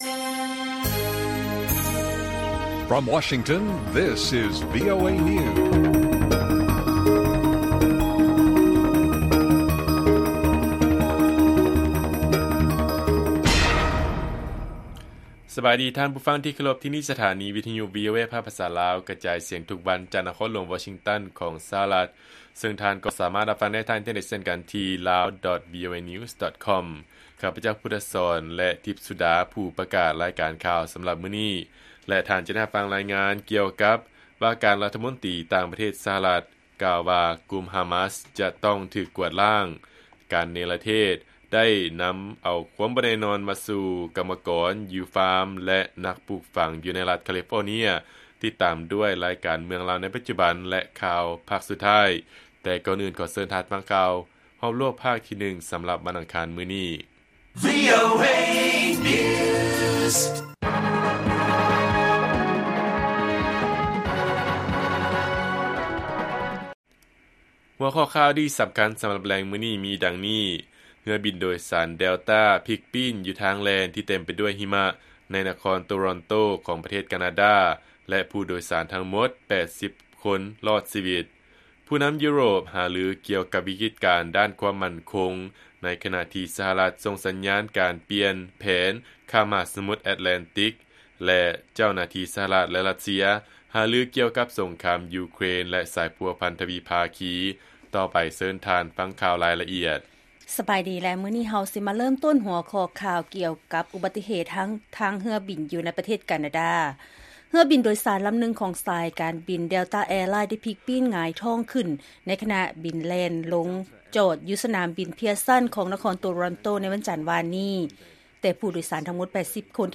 ລາຍການກະຈາຍສຽງຂອງວີໂອເອລາວ: ເຮືອບິນໂດຍສານ ແດລຕ້າ ພິກປີ້ນຢູ່ທາງແລ່ນ ທີ່ເຕັມໄປດ້ວຍຫິມະ ໃນນະຄອນໂຕຣອນໂຕ ແລະຜູ້ໂດຍສານທັງໝົດ 80 ຄົນລອດຊີວິດ